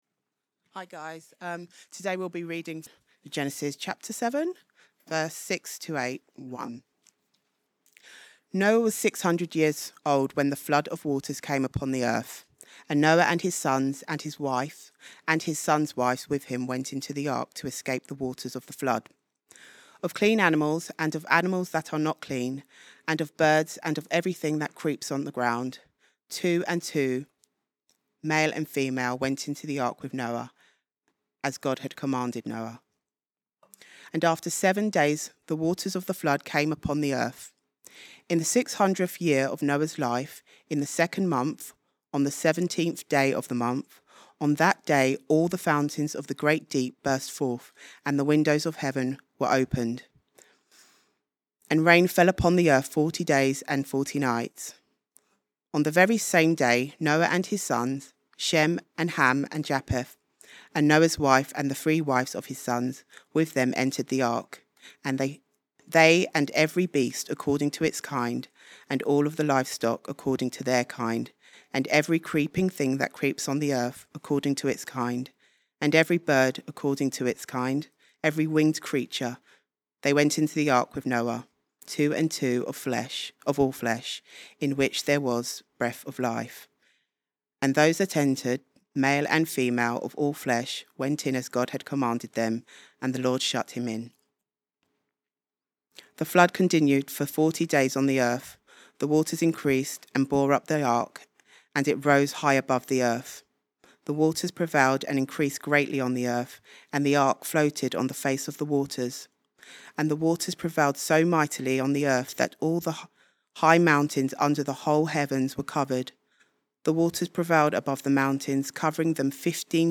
Weekly talks from Christ Church Balham's Sunday service